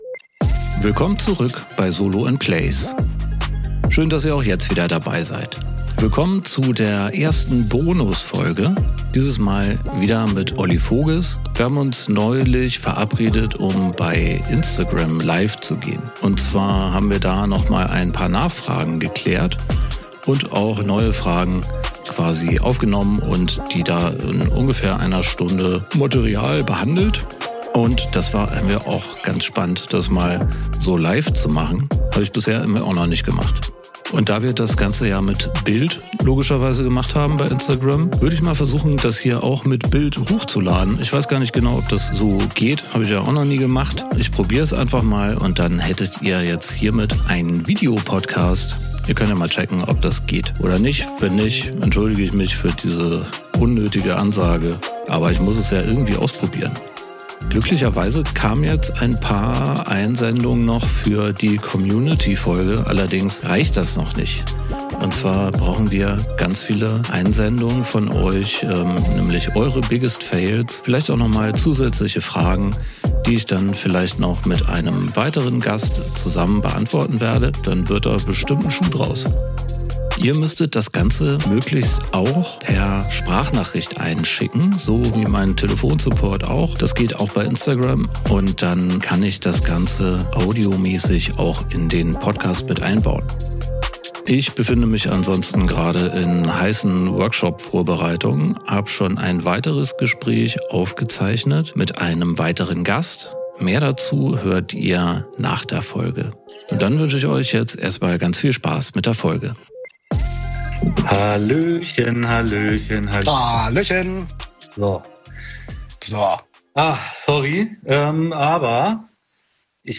Wir waren in einer Livesession, die Ihr Euch hier noch einmal anhören - bzw wenn alles geklappt hat auch ansehen- könnt!